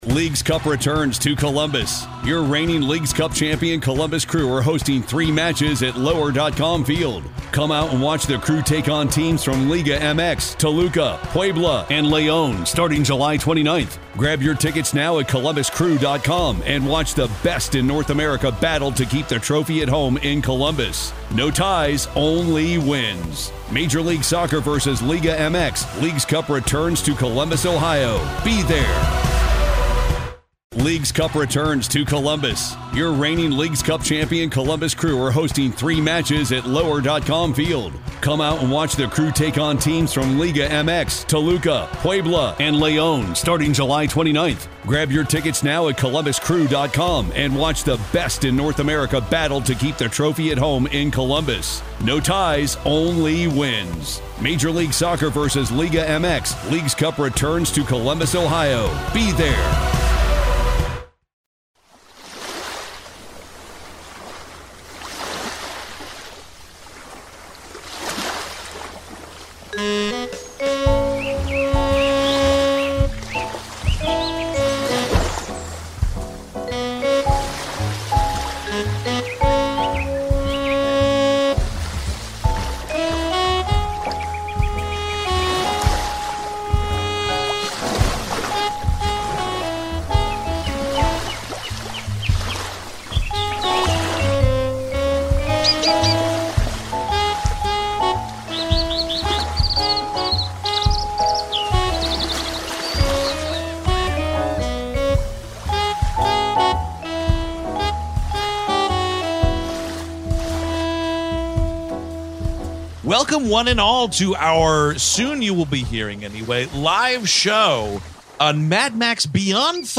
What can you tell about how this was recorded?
On the final summer break episode of the season, it's the gang live-ish from FRQNCY1 talking Mad Max Beyond Thunderdome! Recorded at the beautiful Elsewhere in Brooklyn, NY, this show has the guys asking the big questions like: does Bartertown acknowledge the weekend?